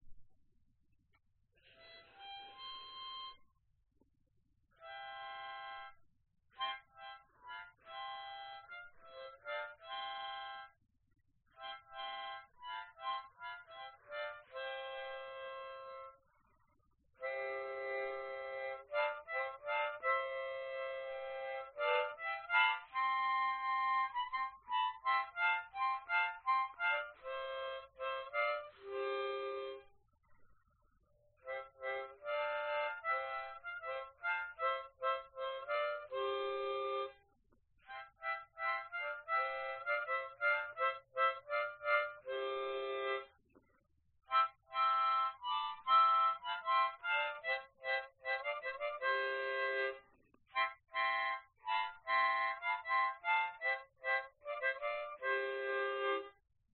口琴跑路3 130 Bpm
Tag: 130 bpm Rock Loops Harmonica Loops 1.55 MB wav Key : G